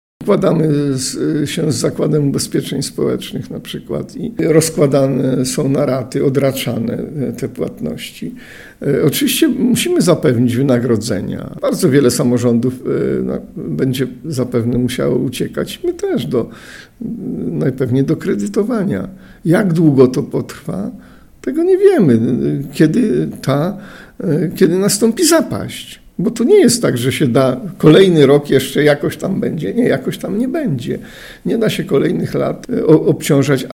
WYSŁUCHAJ całej rozmowy z Józefem Matysiakiem, starostą powiatu rawskiego TUTAJ>>>